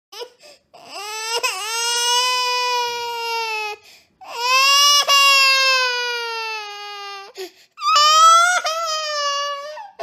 Baby Crying Sound Effect: Unblocked Meme Soundboard